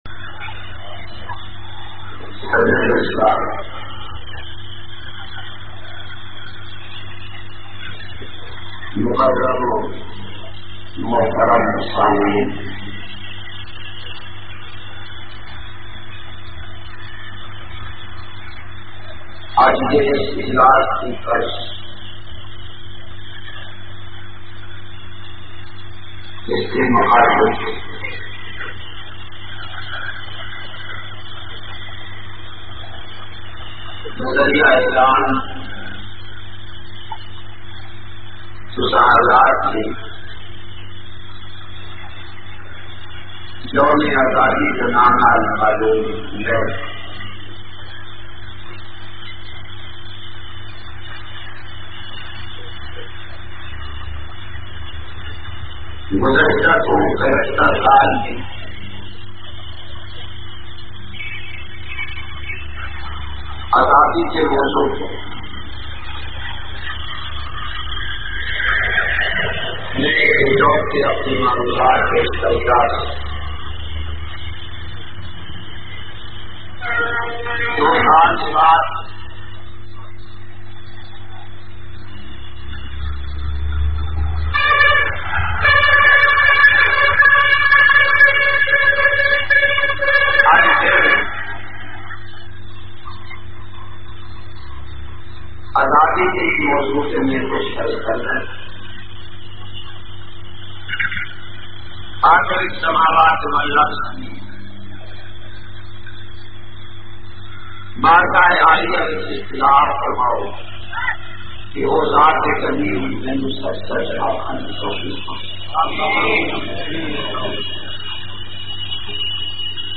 208- Yaum E Pakistan 14 August Punjabi Bayan Jhang.mp3